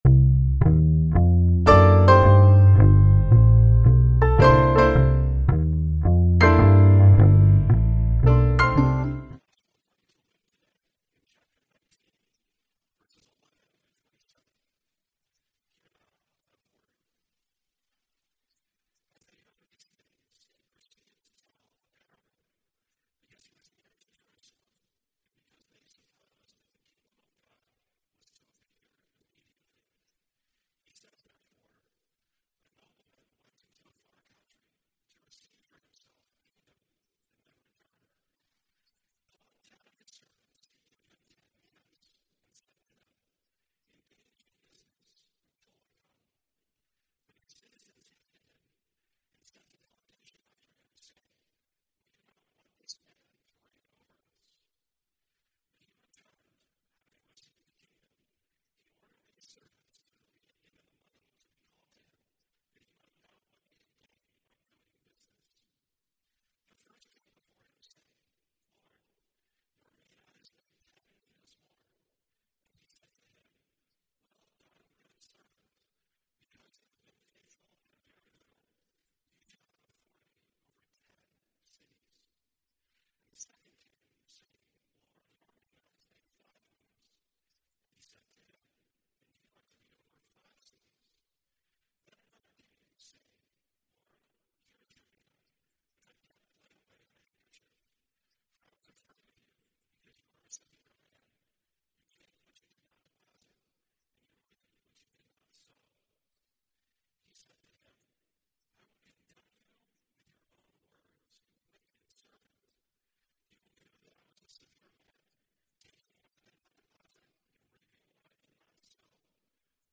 Single Series Sermon Passage: Luke 19:11-27 Service Type: Sunday Worship « What Is Love?